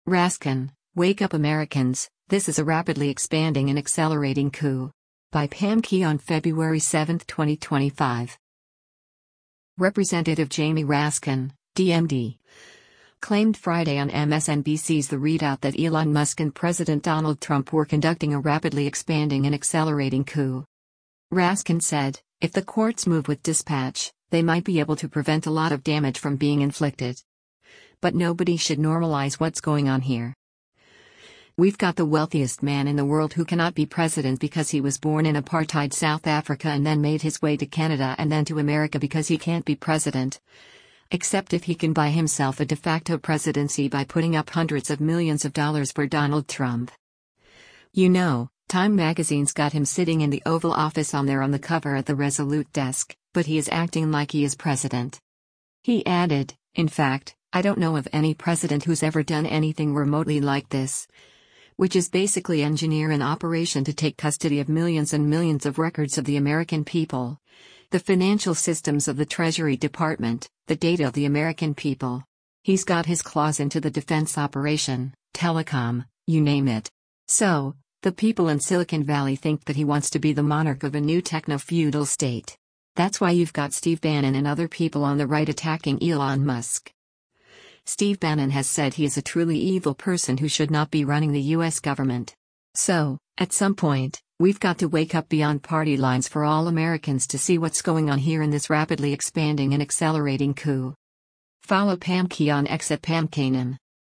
Representative Jamie Raskin (D-MD) claimed Friday on MSNBC’s “The ReidOut” that Elon Musk and President Donald Trump were conducting a “rapidly expanding and accelerating coup.”